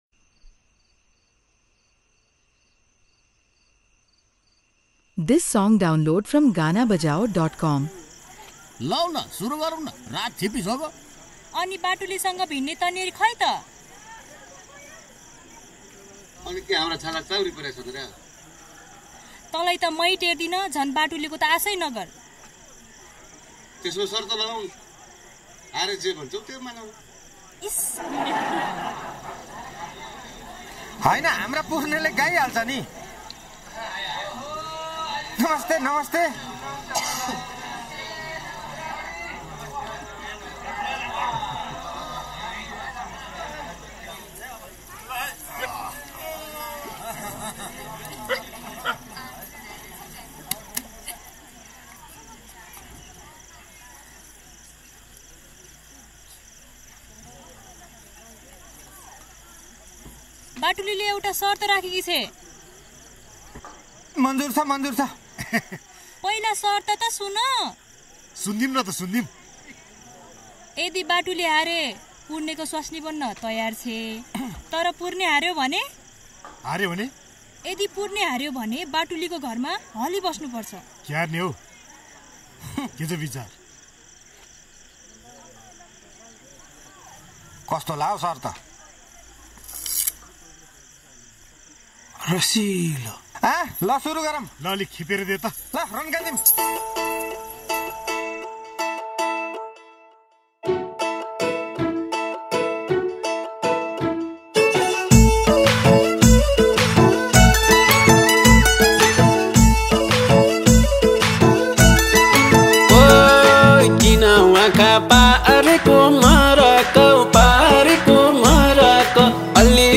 New Dohori Movie Song